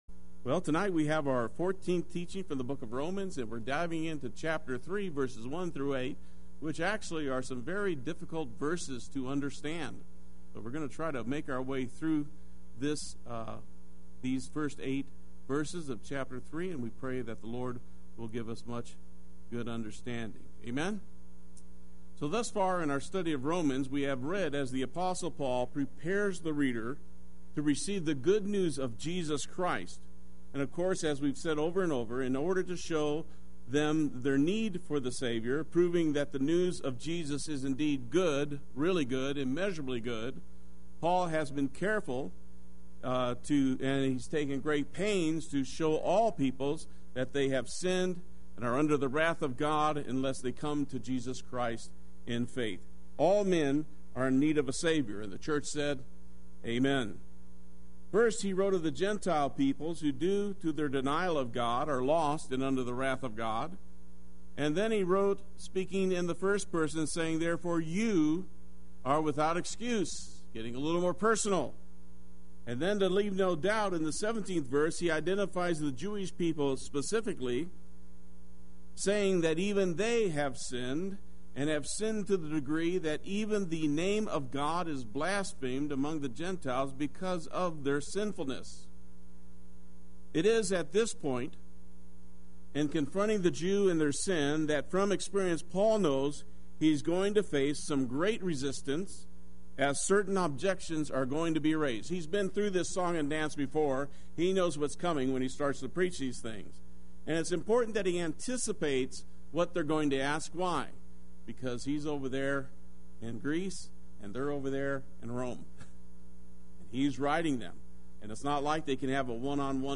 Play Sermon Get HCF Teaching Automatically.
Wednesday Worship